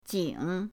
jing3.mp3